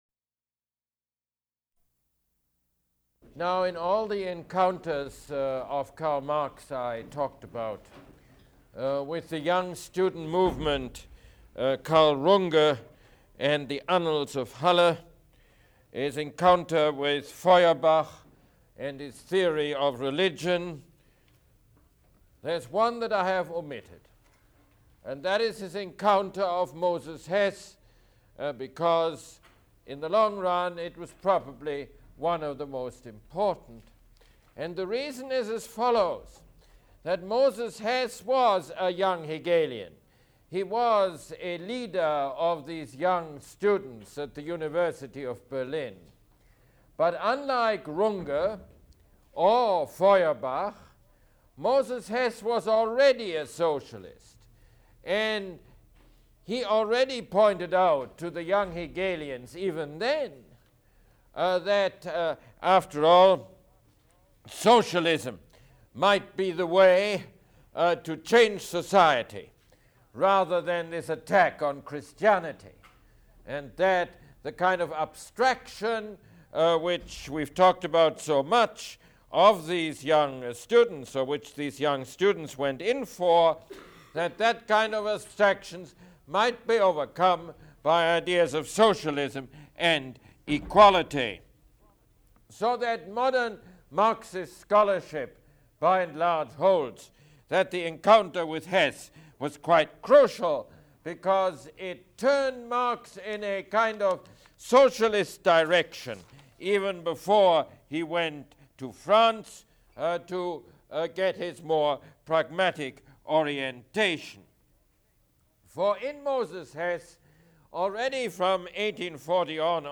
Mosse Lecture #31